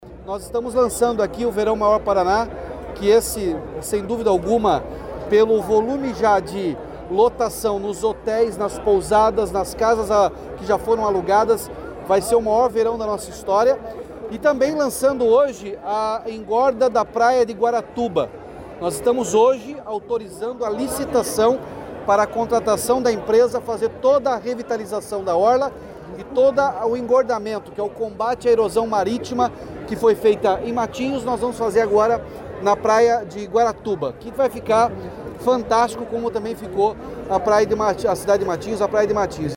Sonora do governador Ratinho Junior sobre o edital para a engorda da praia de Guaratuba